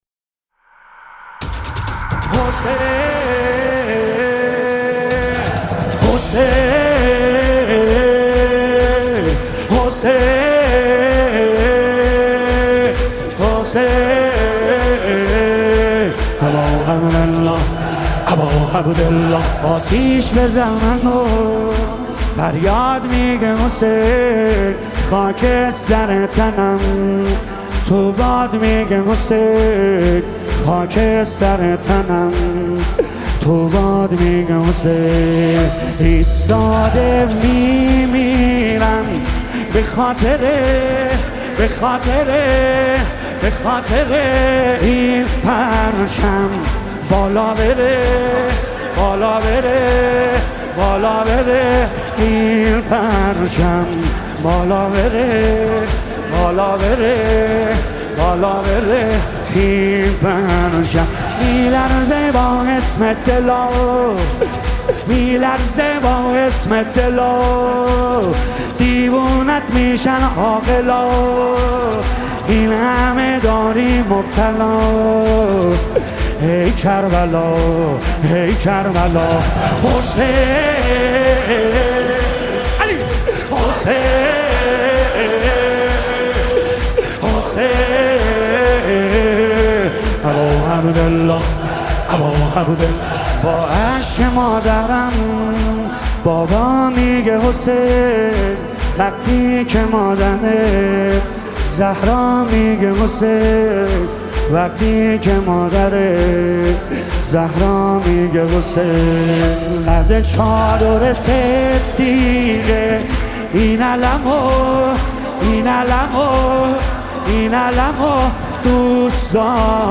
نماهنگ محرم مداحی ماه محرم